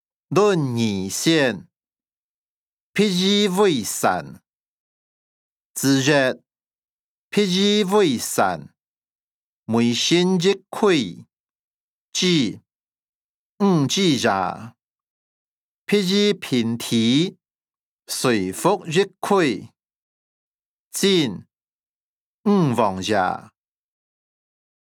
經學、論孟-論語選．譬如為山音檔(饒平腔)